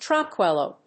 意味・対訳 トランクイッロ、トランクイロ